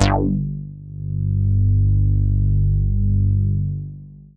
G3_moogy.wav